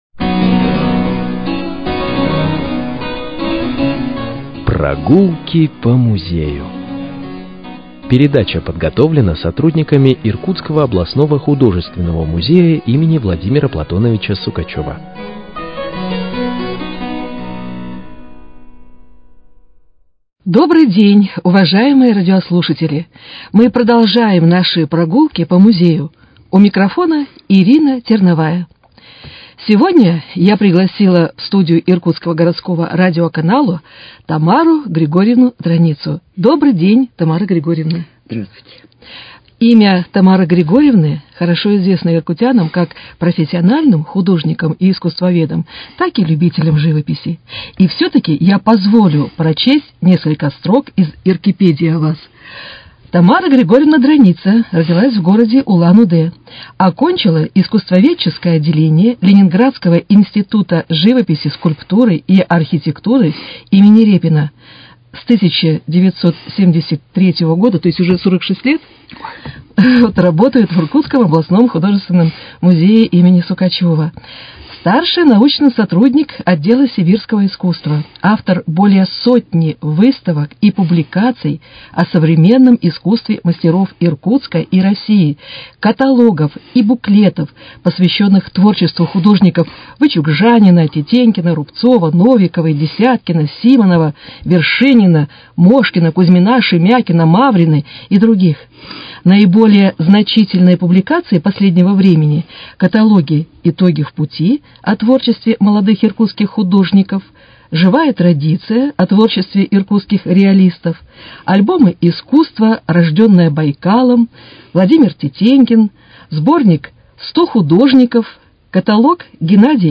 Прогулки по музею: Беседа